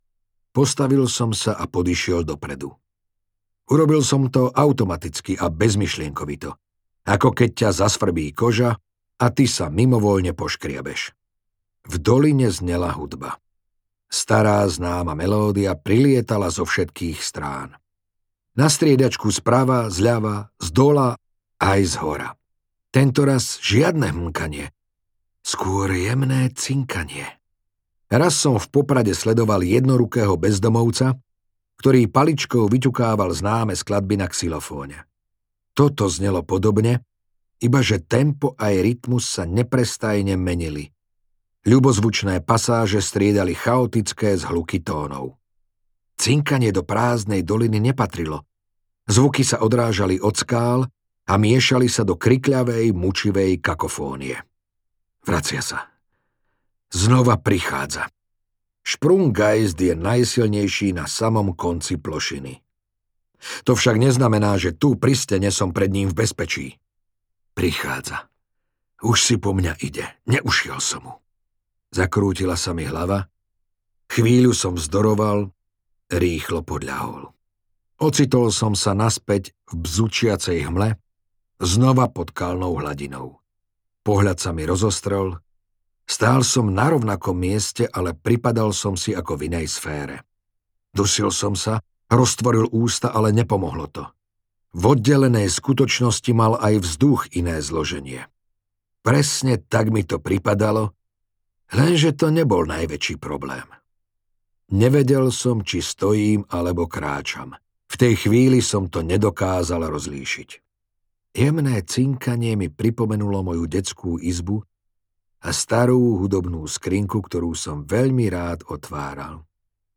Priepasť audiokniha
Ukázka z knihy